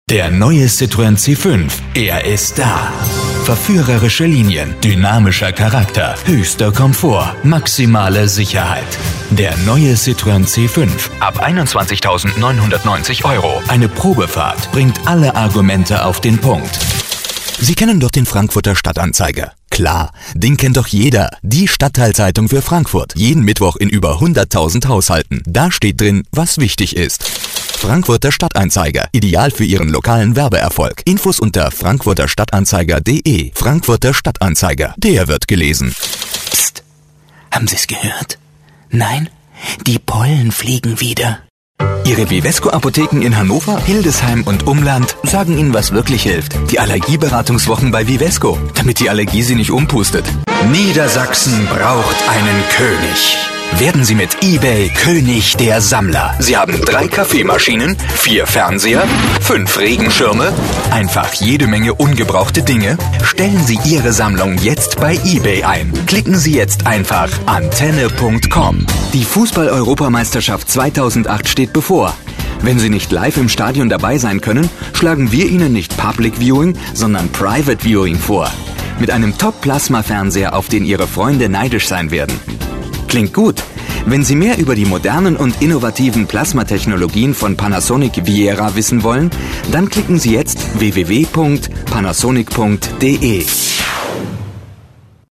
Sprecher englisch (usa) mit einem großen schauspielerischen Spektrum
Sprechprobe: Werbung (Muttersprache):
voice over artist english (us)